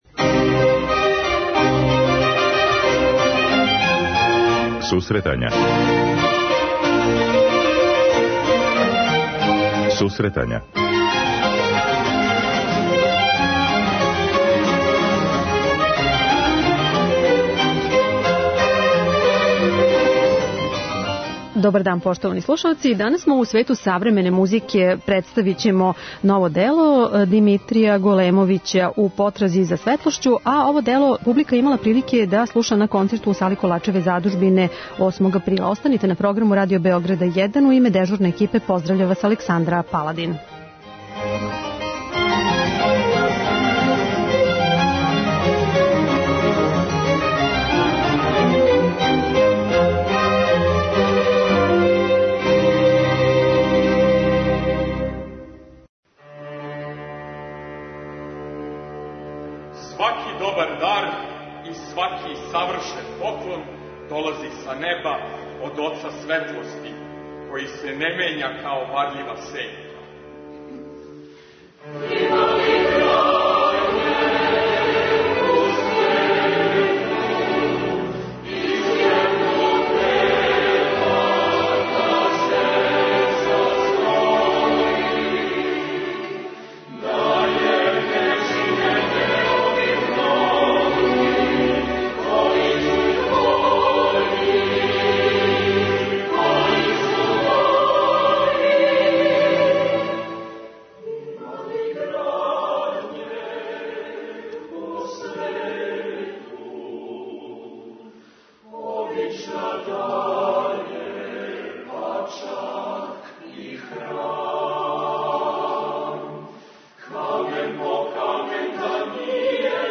преузми : 10.79 MB Сусретања Autor: Музичка редакција Емисија за оне који воле уметничку музику.